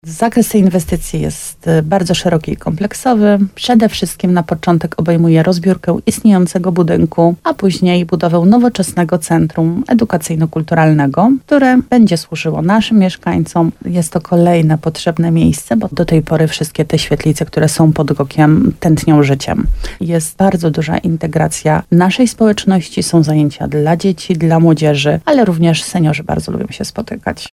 Jak mówi wójt Marta Słaby, urzędnicy jeszcze analizują propozycje firm i weryfikują dokumenty.